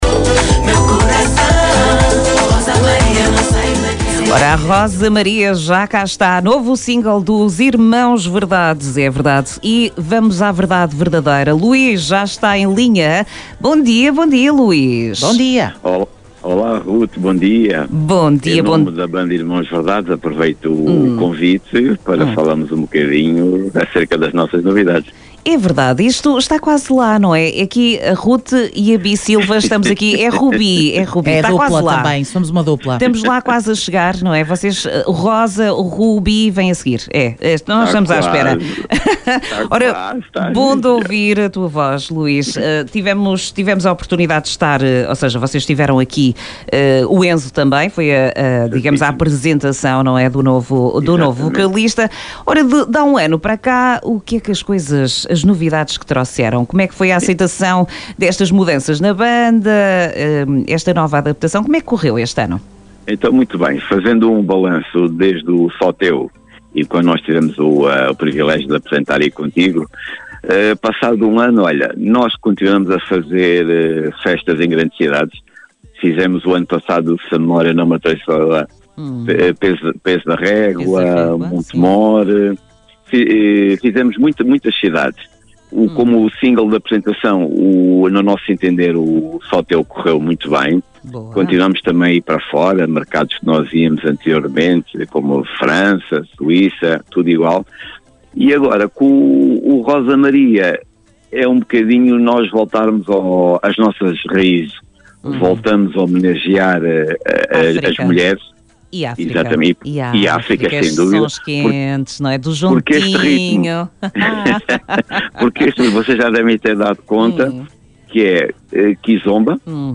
ENTREVISTA-IRMAOS-VERDADES-MAI2025.mp3